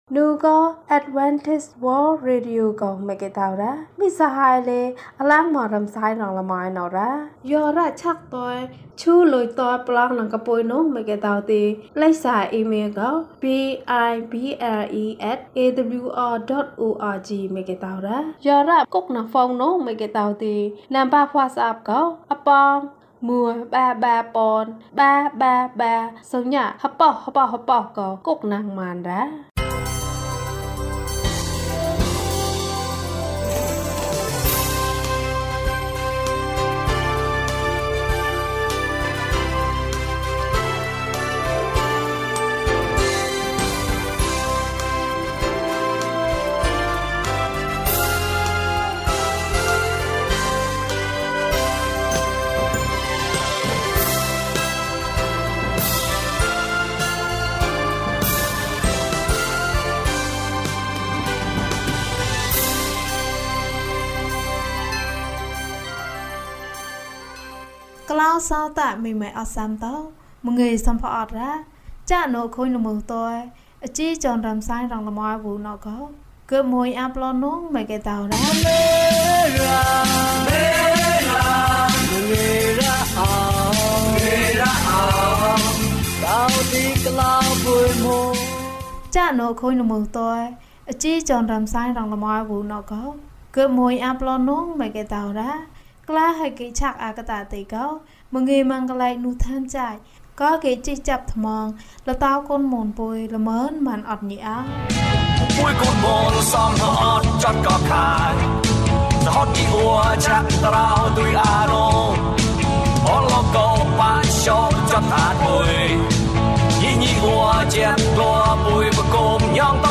ဘုရားသခင်ထံမှ ကောင်းကြီးပေးခြင်း။၂၃ ကျန်းမာခြင်းအကြောင်းအရာ။ ဓမ္မသီချင်း။ တရားဒေသနာ။